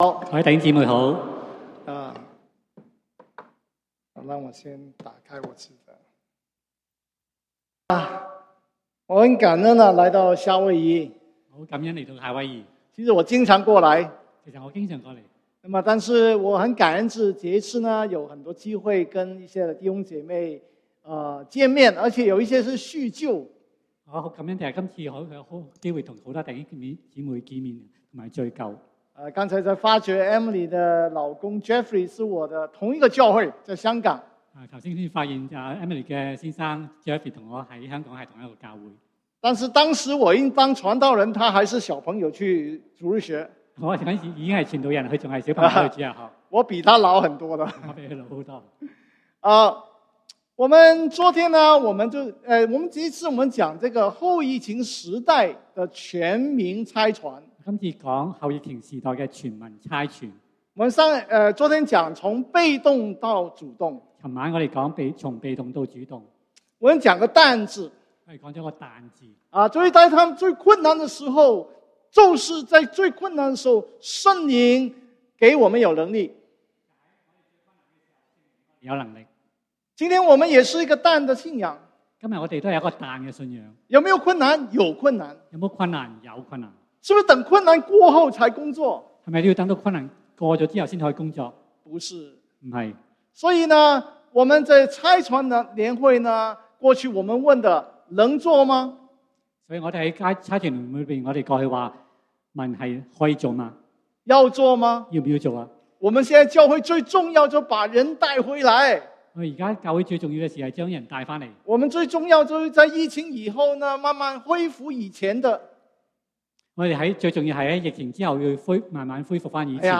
2022 宣教年會 --- 後疫情時代的全民差傳 (二) 從聽道到行動